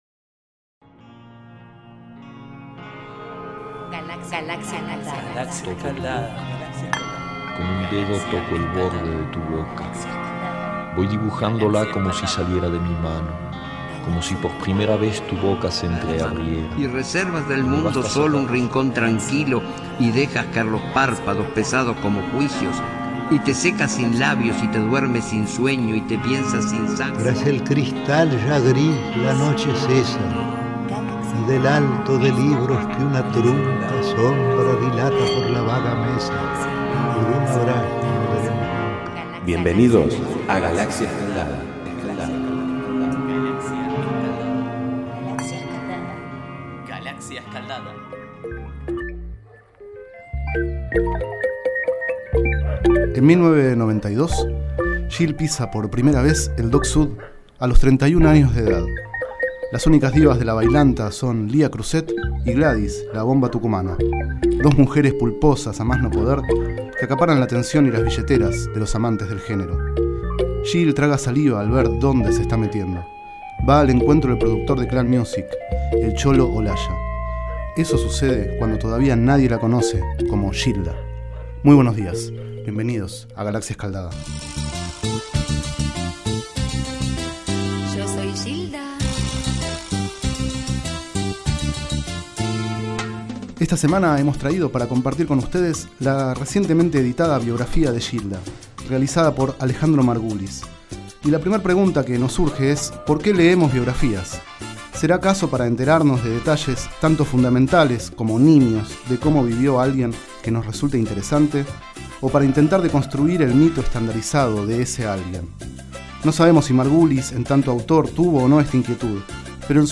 Este es el 32º micro radial, emitido en los programas Enredados, de la Red de Cultura de Boedo, y En Ayunas, el mañanero de Boedo, por FMBoedo, realizado el 20 de octubre de 2012, sobre el libro Gilda, la abanderada de la bailanta, de Alejandro Margulis.